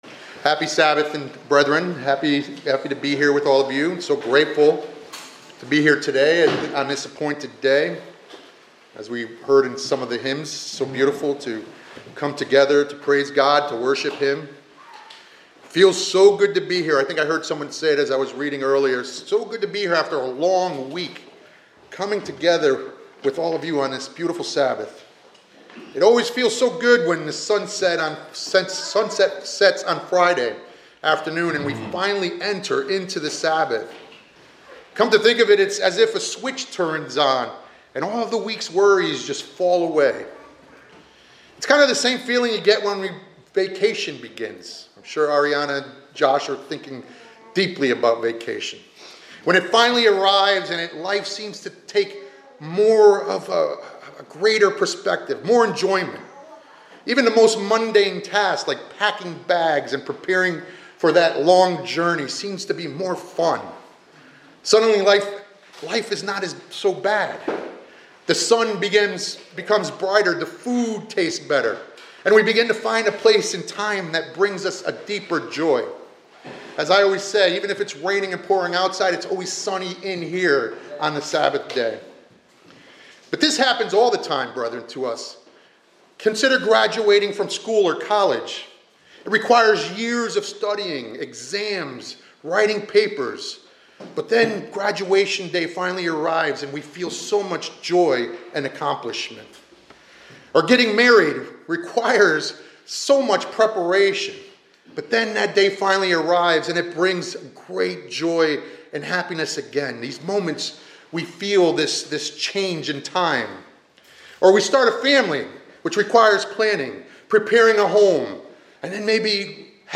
This sermon discusses the significance of time, particularly the distinction between Chronos (sequential time) and Kairos (appointed time).